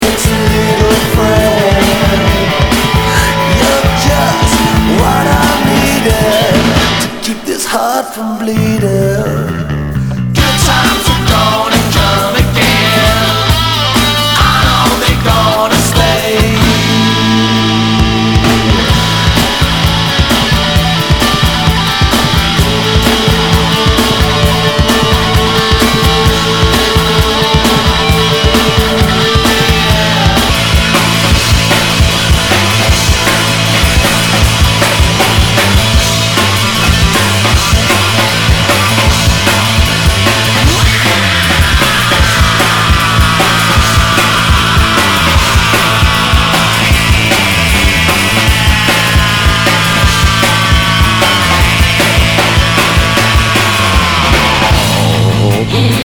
ROCK/POPS/INDIE
ナイス！ヘビーメタル！！
[VG ] 平均的中古盤。スレ、キズ少々あり（ストレスに感じない程度のノイズが入ることも有り）